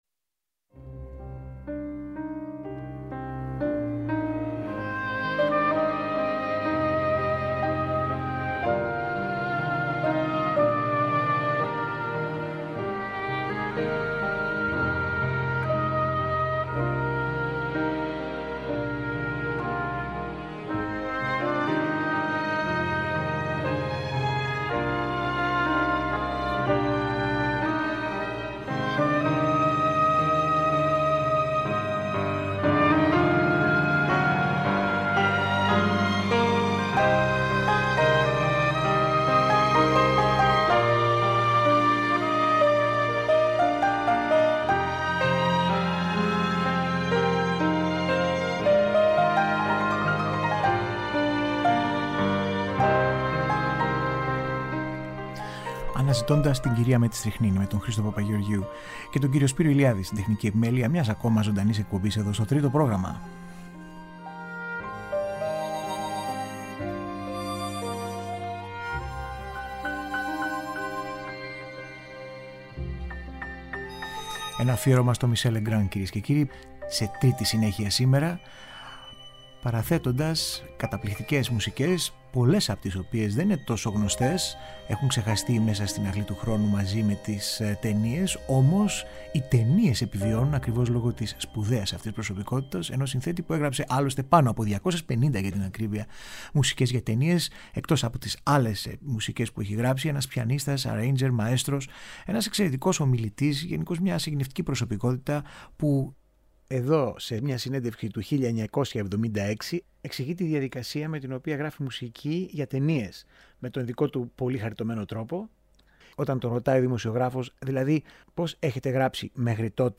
Κινηματογραφικη Μουσικη